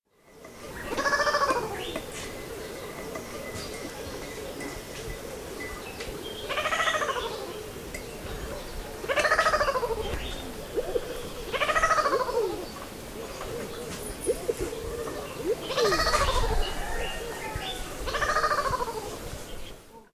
birdsounds